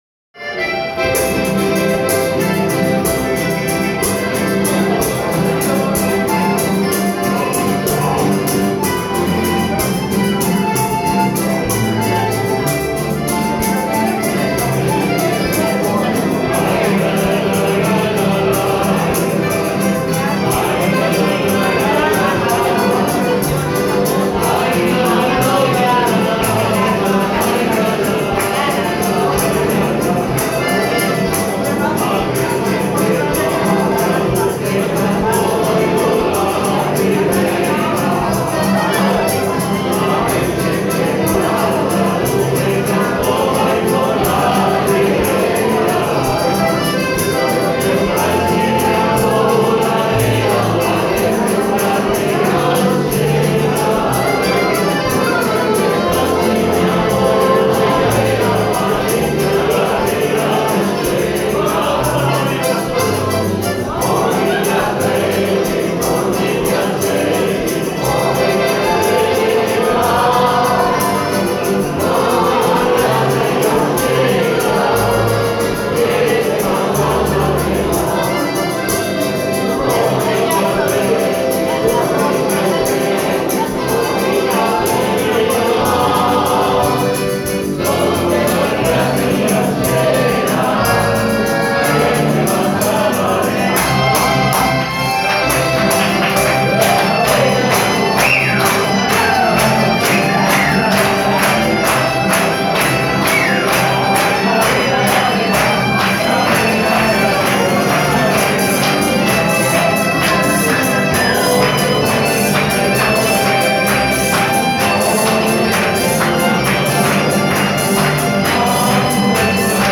Une atmosphère de fête est entretenue par troubadours, mimes et amuseurs de toutes sortes. Une bande à ne pas manquer s’approprie l’entrée du bâtiment du gouvernement tous les soirs et leur énergie est contagieuse.
Ouïe: La musique des joyeux troubadours sous le porche de la bâtisse du gouvernement